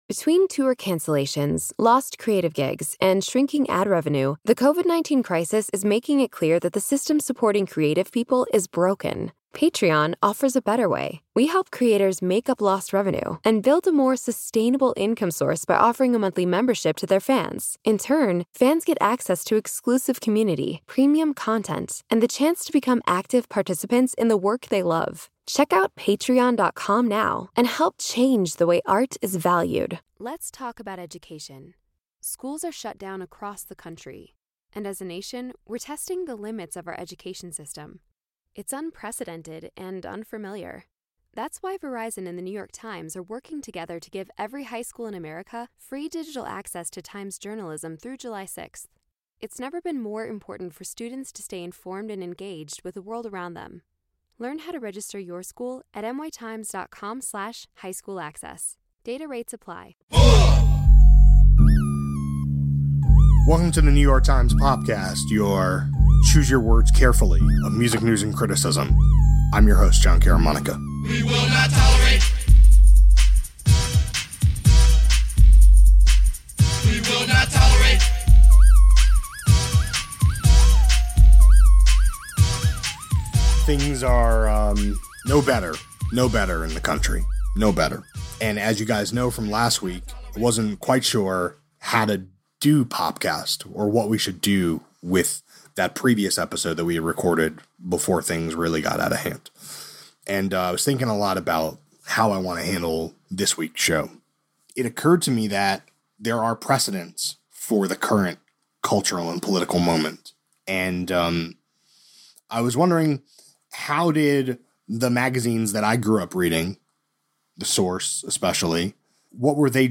A conversation about the moral and journalistic responsibilities of entertainment-focused media to address the political issues brought up by the art it covers.